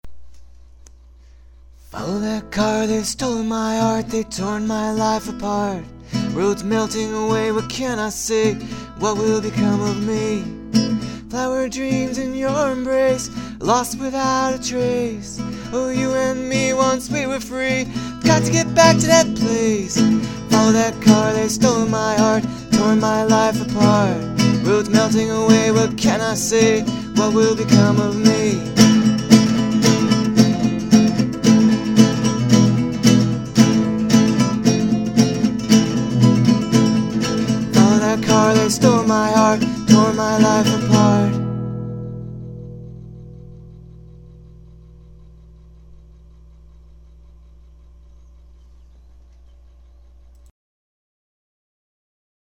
MP3 song demo